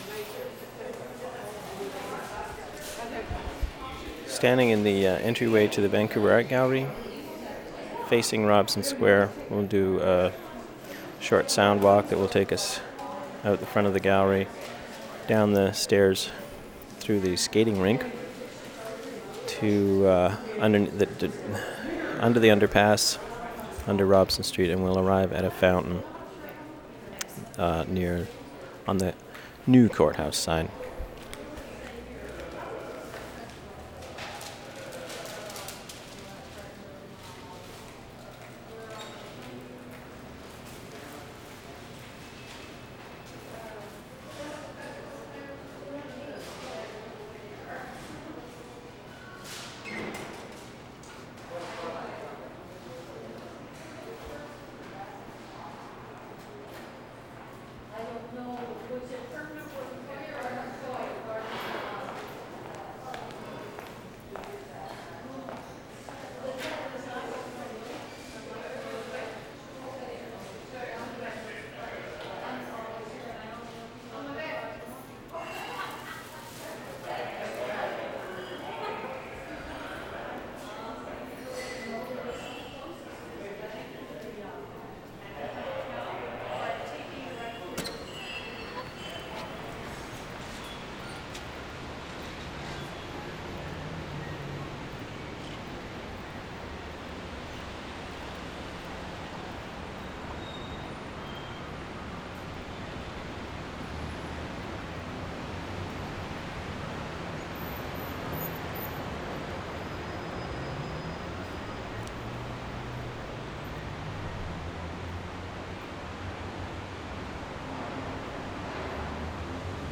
soundwalk from Art Gallery to new courthouse 8:36
13. ID, talking, footsteps inside gallery, walkie-talkie at 1:26, through door at 1:30, traffic, distant music, fountain, muzak at 2:52, fountains get louder and quieter as they are passed by the mic, car horn at 7:20, footsteps at 7:35, air brakes at 7:53, ID at 8:17